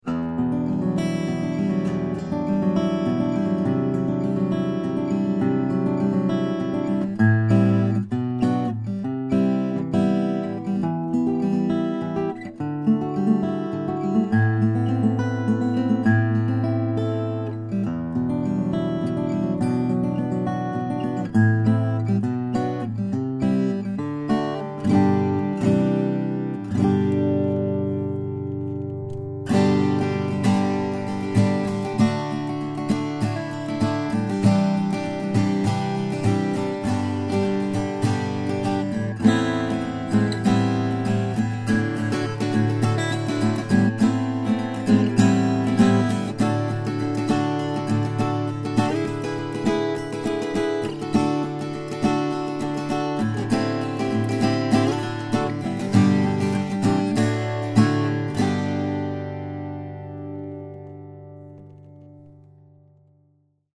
The Indian Rosewood back and sides, and complimentary Engelmann Spruce Double Top give this superbly built guitar a detailed, harmonically rich tone and excellent dynamic range.